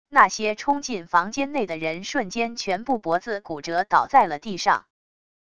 那些冲进房间内的人 瞬间全部脖子骨折 倒在了地上wav音频生成系统WAV Audio Player